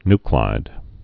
(nklīd, ny-)